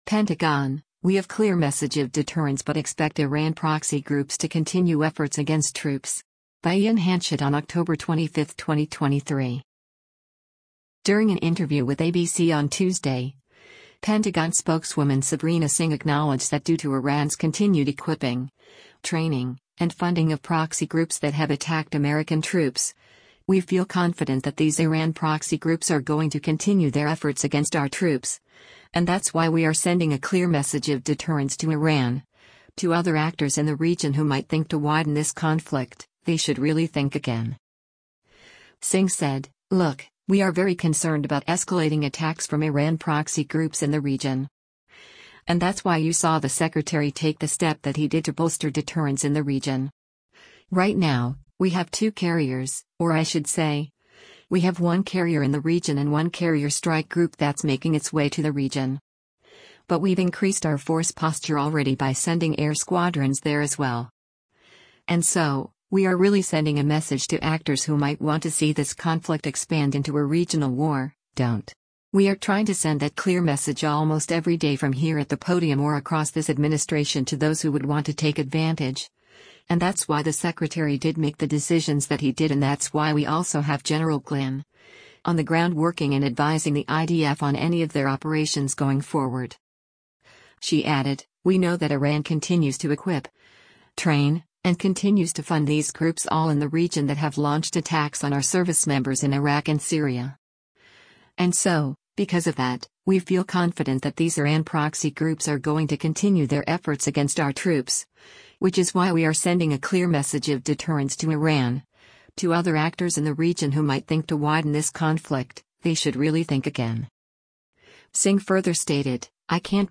During an interview with ABC on Tuesday, Pentagon Spokeswoman Sabrina Singh acknowledged that due to Iran’s continued equipping, training, and funding of proxy groups that have attacked American troops, “we feel confident that these Iran proxy groups are going to continue their efforts against our troops,” and that’s “why we are sending a clear message of deterrence to Iran, to other actors in the region who might think to widen this conflict, they should really think again.”